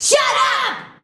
SHUT UP.wav